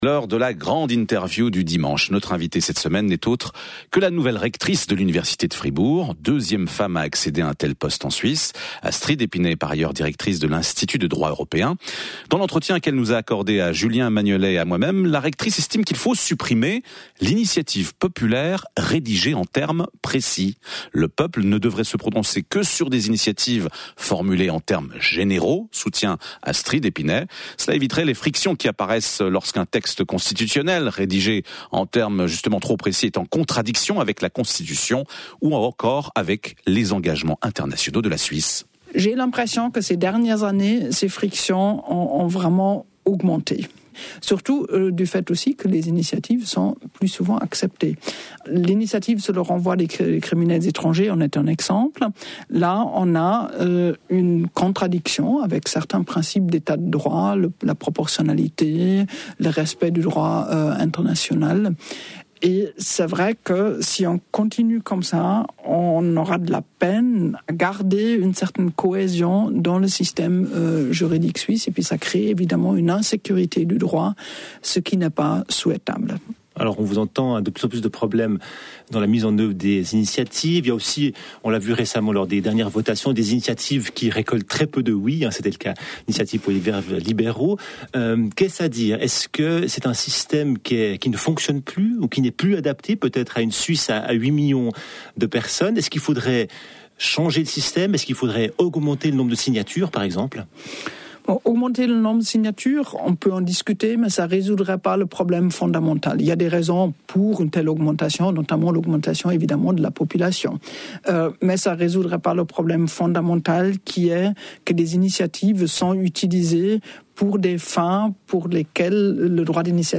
Invitée de la grande interview du dimanche dans l'émission Forum de La Première